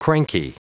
Prononciation du mot cranky en anglais (fichier audio)
cranky.wav